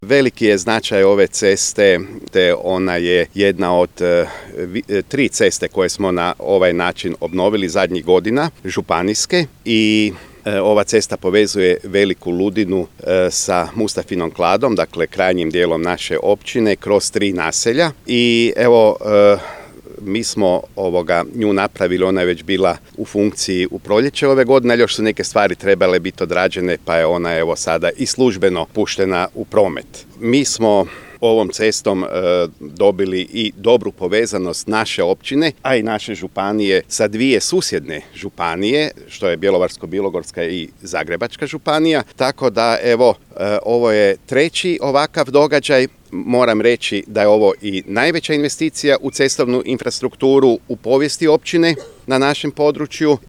FOTO | Svečano otvorena cesta Mala Ludina-Kompator-Mustafina Klada